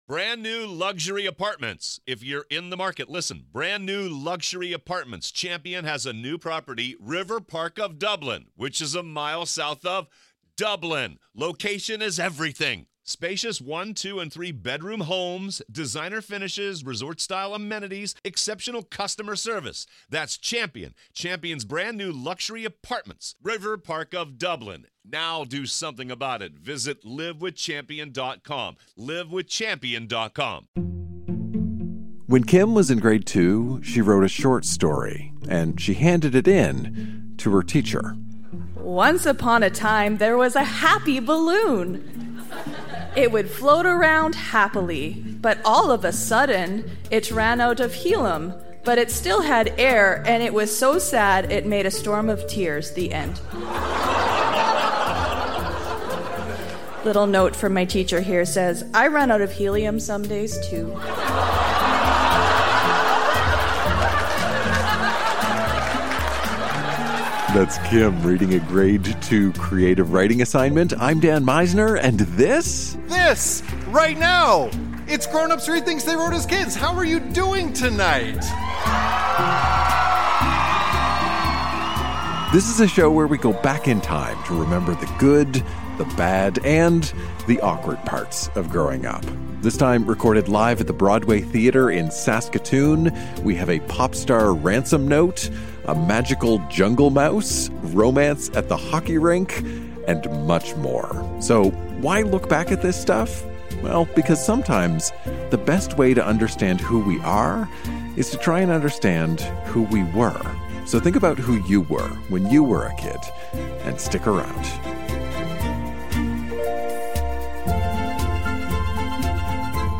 Recorded live at The Broadway Theatre in Saskatoon.